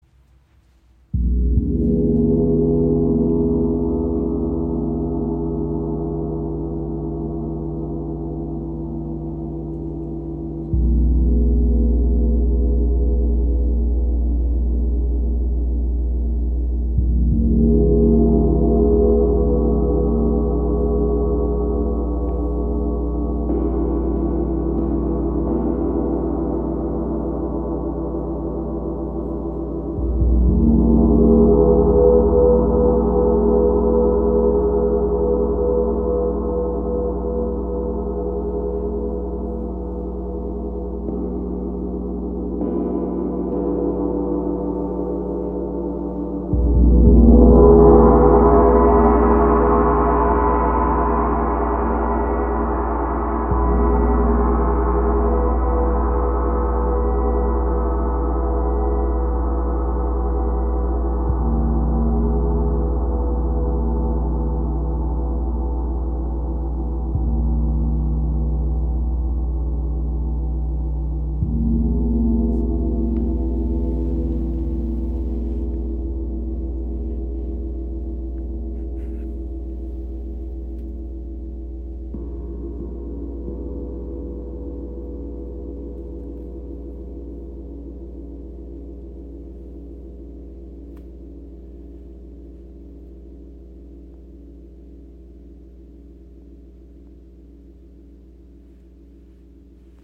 • Icon Jeder Gong ist handgefertigt und einzigartig.
• Icon Bronze und Nickel-Silber erzeugen warme, harmonische Klänge.